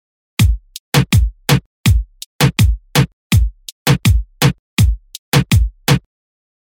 2：ハイハットをもっと刻む
ハイハットを「1」の倍刻むパターンです。
「1」とは印象が変わり、「いかにもレゲトン」感がちょっとだけ薄まります。
reggaeton3.mp3